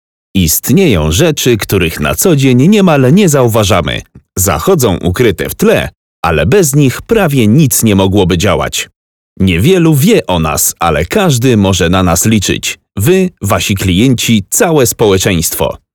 Kommerziell, Tief, Natürlich, Zuverlässig, Freundlich
Unternehmensvideo
Flexible, energetic and charismatic voice.